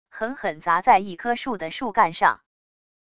狠狠砸在一棵树的树干上wav音频